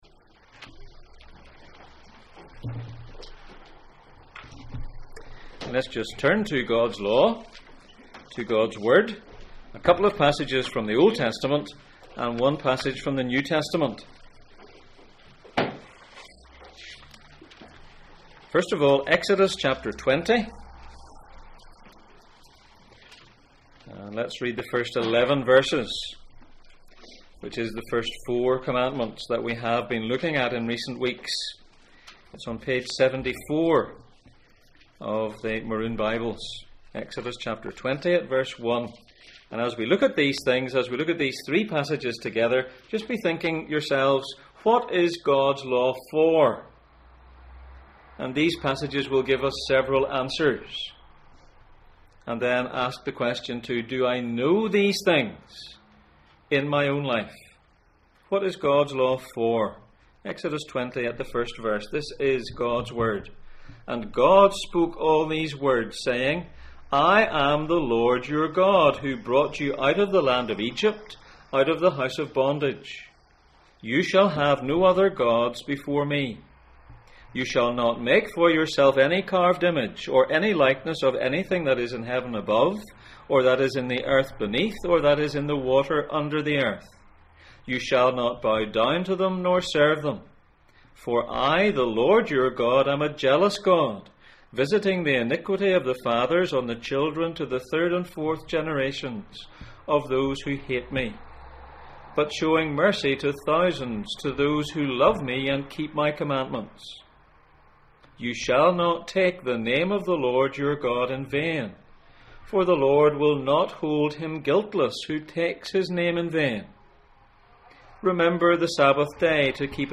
Gods instructions for life Passage: Exodus 20:1-11, Psalm 119:44-48, Romans 7:7-12, Romans 7:22-25, Romans 10:4, Romans 8:4 Service Type: Sunday Morning